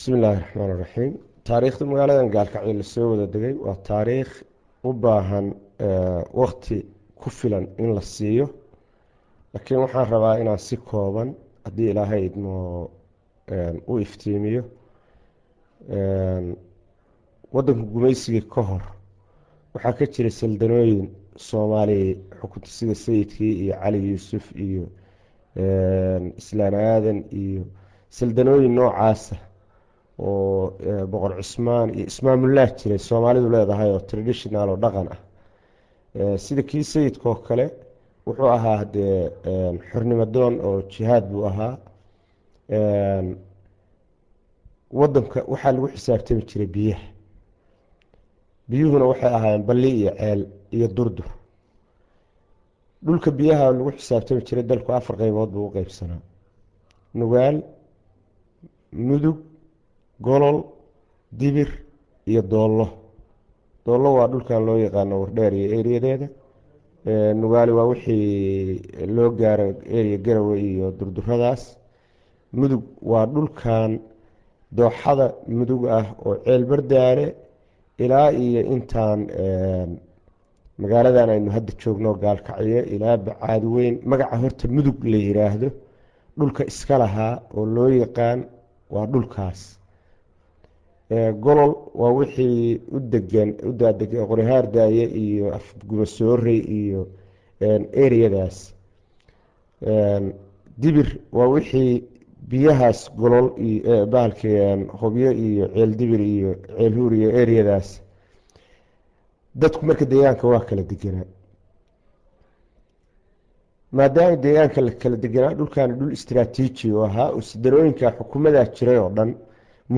wareysi dheer